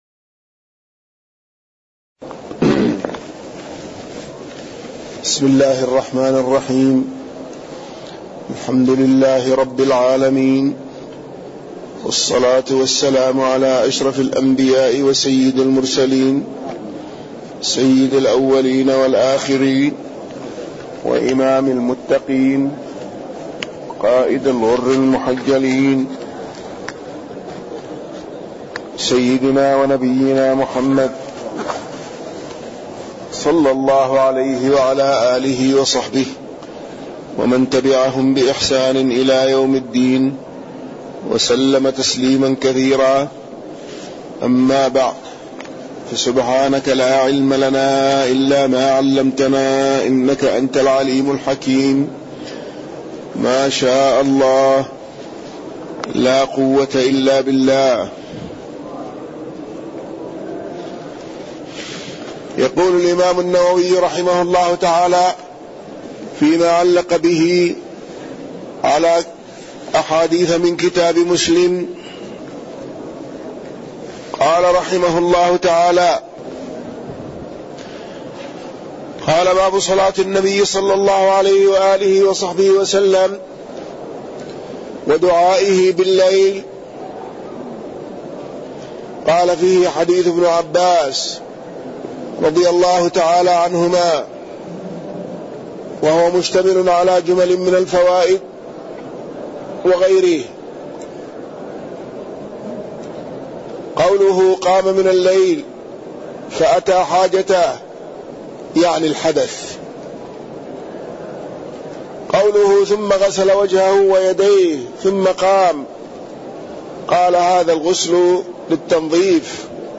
تاريخ النشر ٩ صفر ١٤٣١ هـ المكان: المسجد النبوي الشيخ